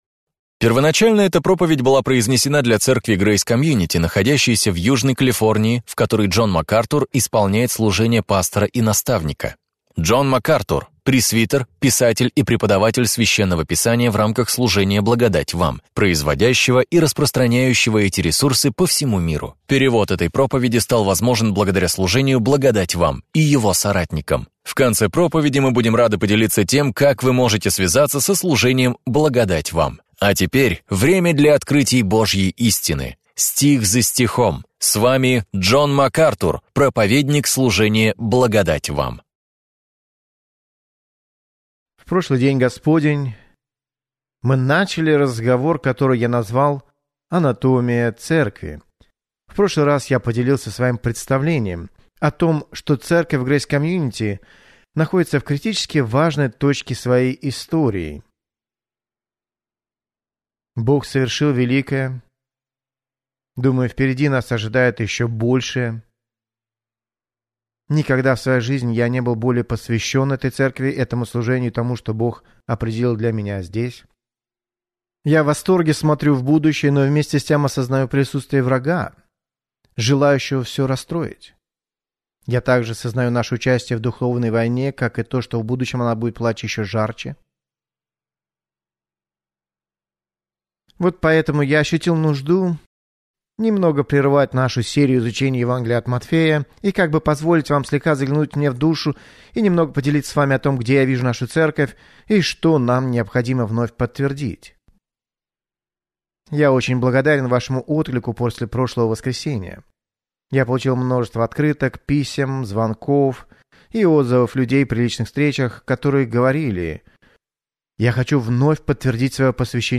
«Анатомия Церкви» – это ценная проповедь, поясняющая, как вы и ваша церковь можете прославлять Бога!